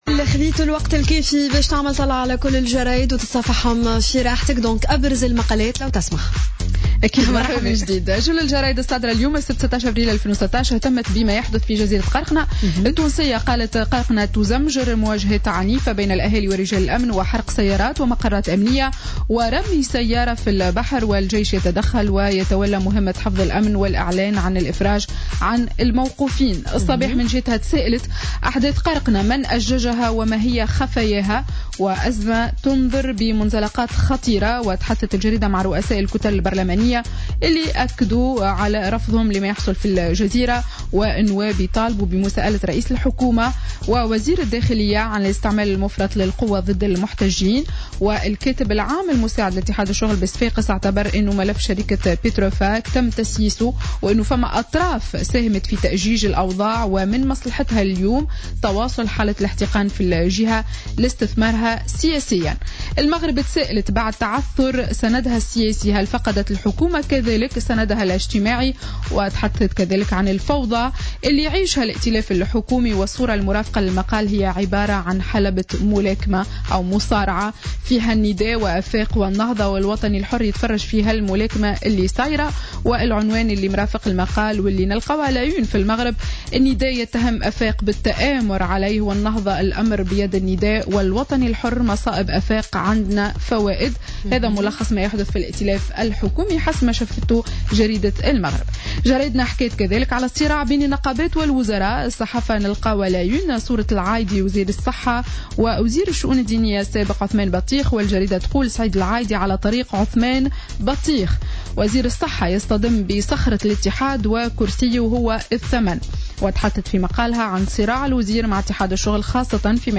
Revue de presse du Samedi 16 Avril 2016